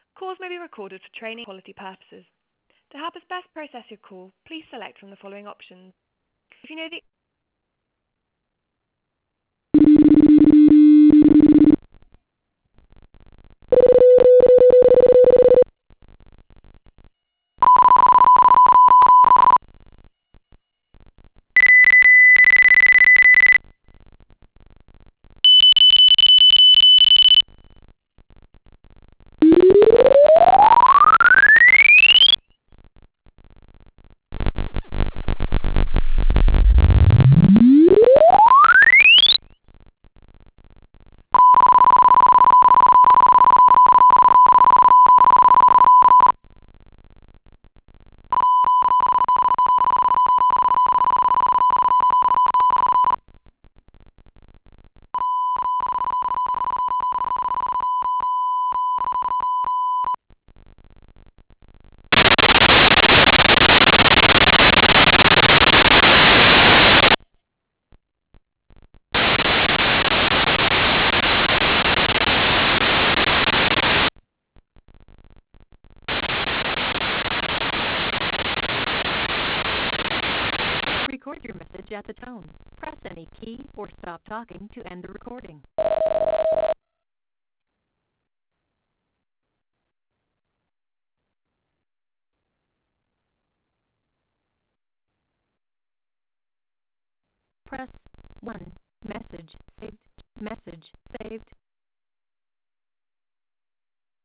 [Freeswitch-users] Choopy one-way noise (FreeTDM)
message is perfectly clear, and the voicemail outgoing message (test
sometimes not, sometimes the IVR message goes choppy as well.
choppy_voice.wav